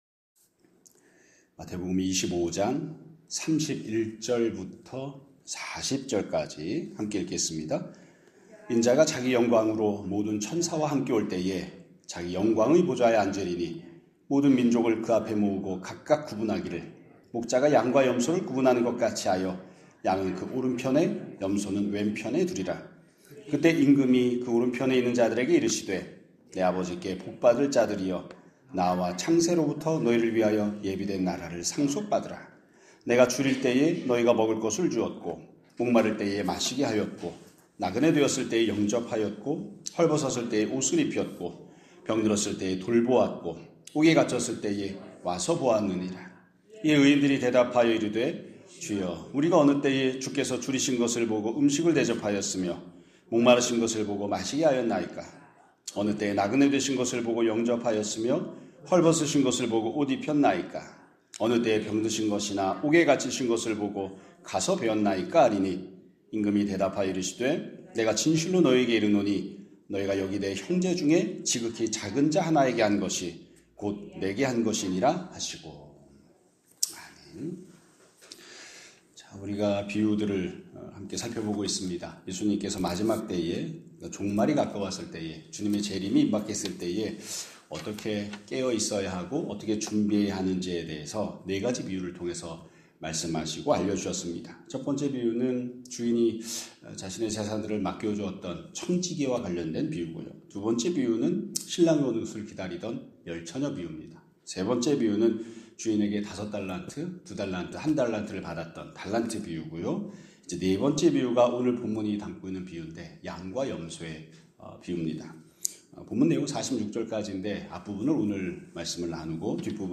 2026년 3월 23일 (월요일) <아침예배> 설교입니다.